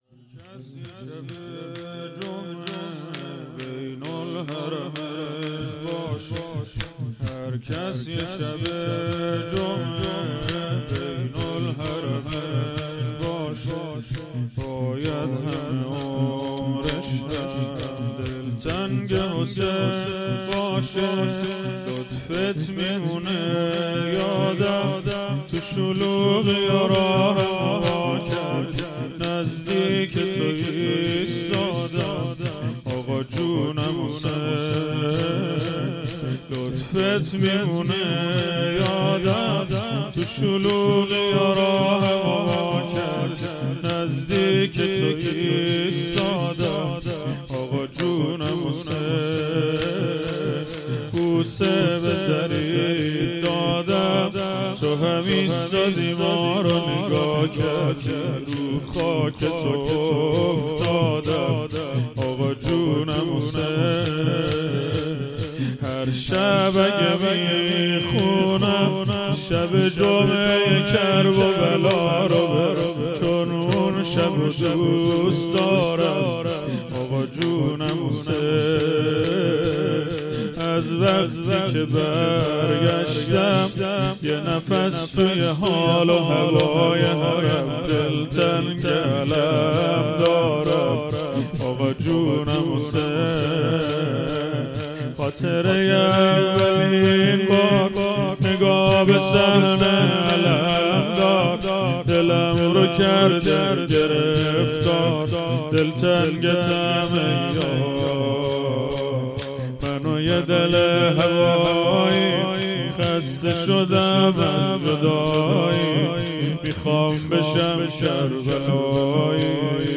0 0 شور
مراسم شب سوم شهادت امام سجاد علیه السلام ۱۴۰۱